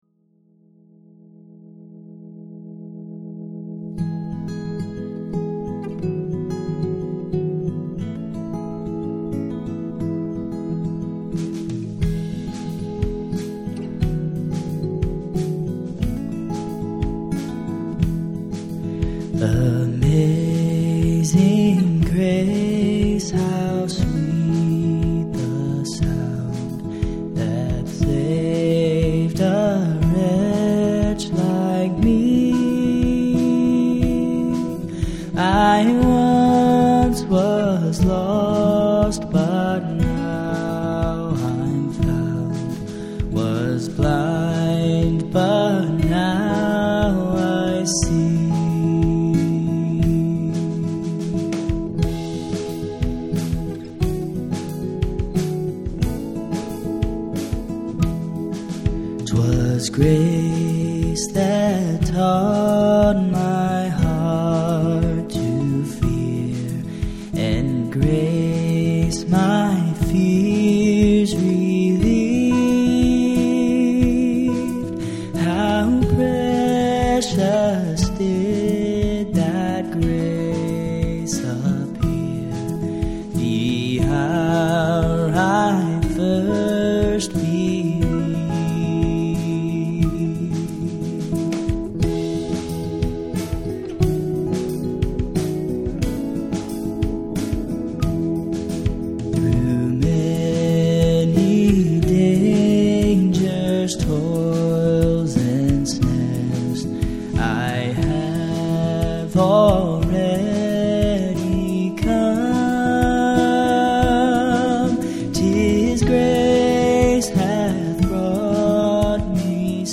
We celebrate to the grace of God that he has lavished on us as we sing this traditional hymn set to a new arrangement.